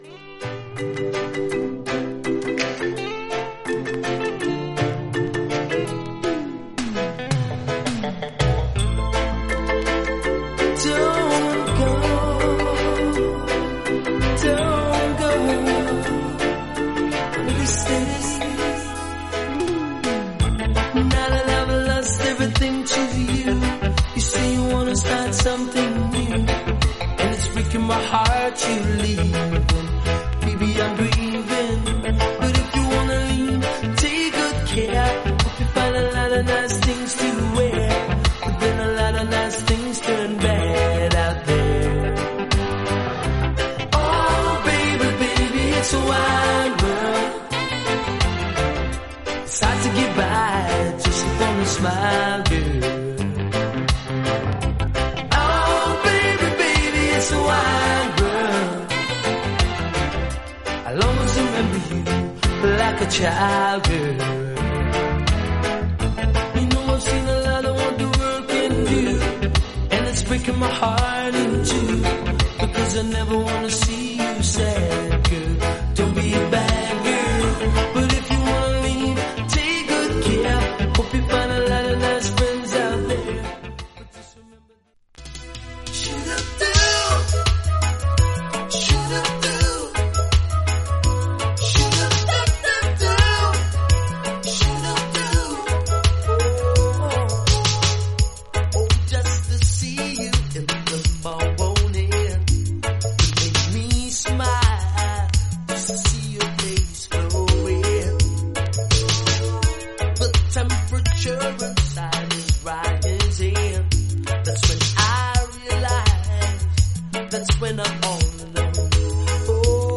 原曲の良さを崩すことなく見事にレゲエ・アレンジでカバーした、タイムレスな名曲です。